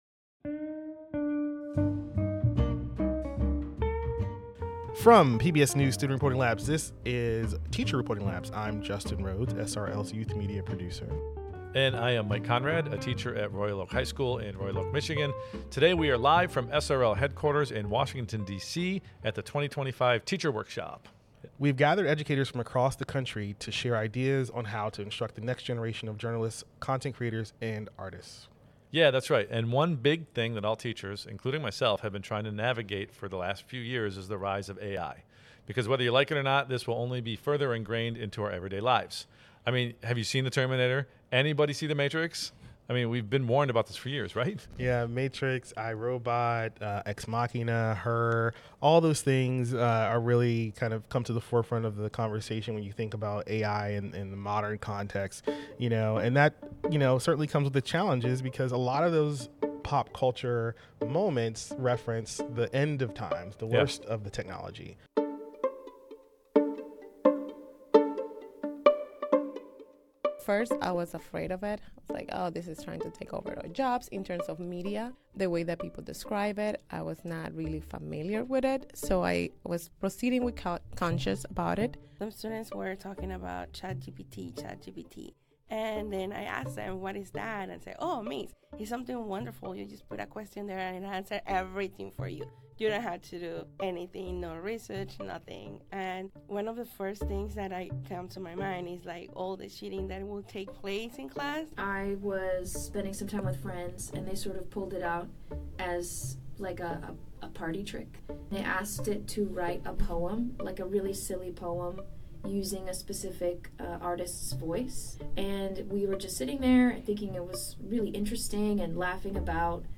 Teacher Reporting Labs (TRL) is a teacher-led podcast where educators report back on a mix of topics, triumphs and challenges they face in the classroom through exploratory and candid conversations.